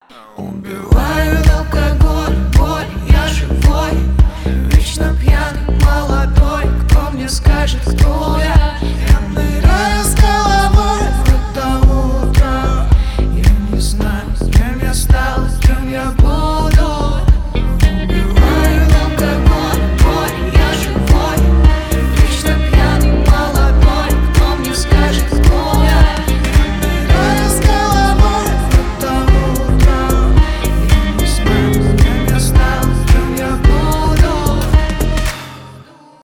альтернатива